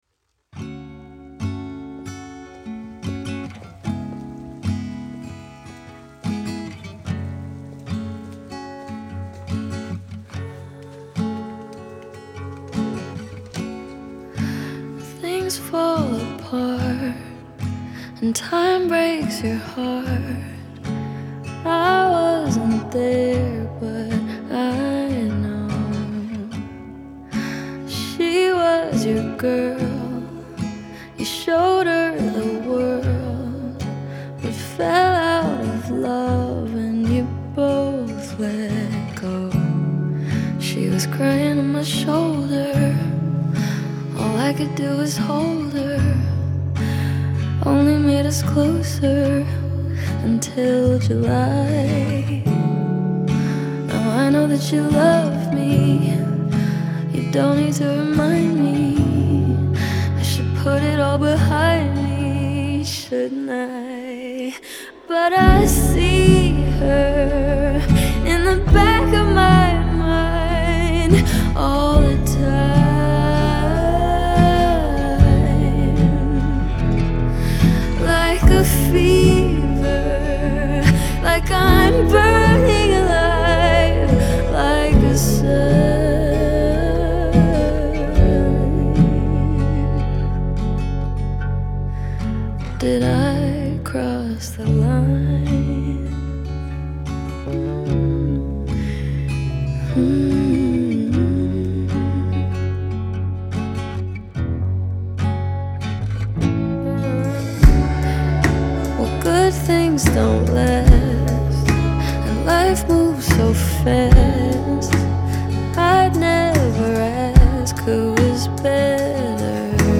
• Жанр: Indie